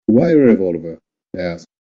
Text-to-Speech
Add generated mp3 files